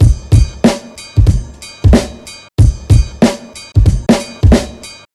鼓手Breakbeat Kit1 Loop1 93BPM
Tag: 93 bpm Breakbeat Loops Drum Loops 889.16 KB wav Key : Unknown